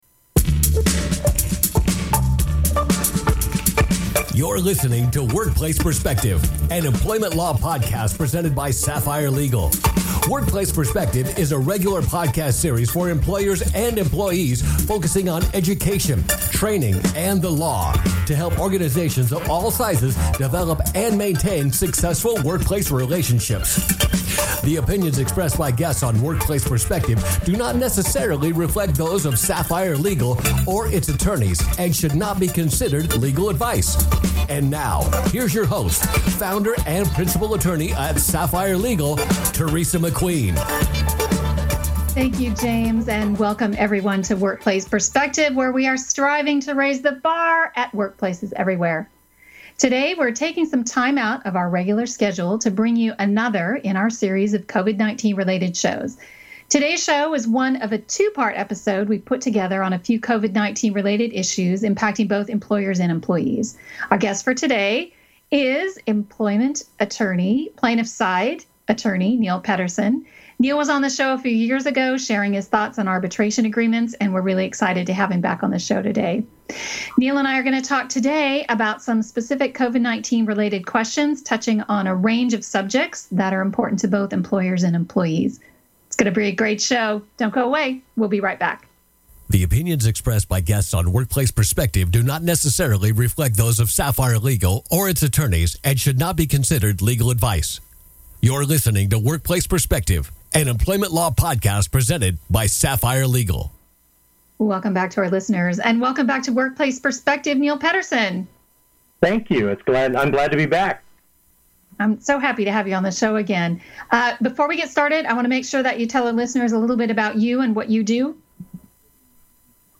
Rest assured that we at Workplace Perspective, in compliance with California's shelter in-place order, are broadcasting for the foreseeable future from our make-shift home studios.